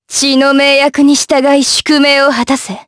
Lewsia_B-Vox_Skill5_jp_b.wav